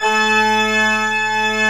Index of /90_sSampleCDs/AKAI S6000 CD-ROM - Volume 1/VOCAL_ORGAN/CHURCH_ORGAN
ORG D3MF  -S.WAV